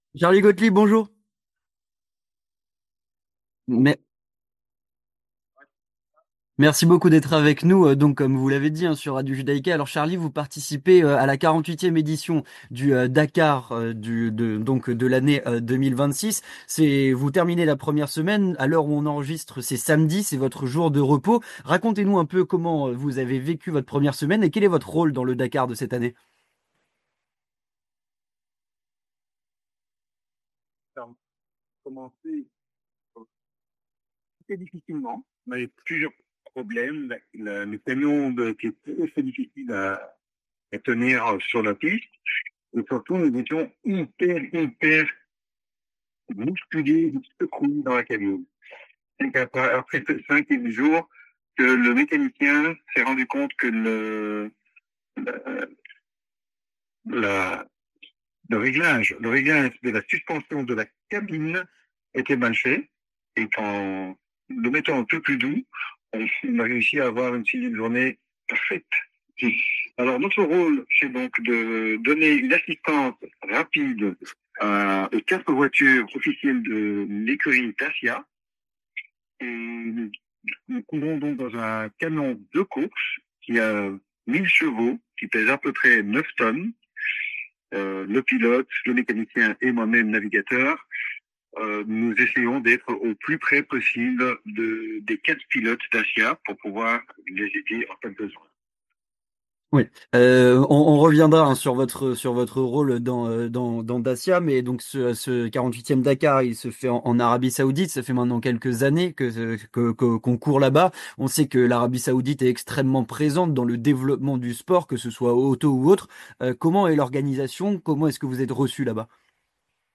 En direct du 46ème Dakar en Arabie Saoudite (12/01/26)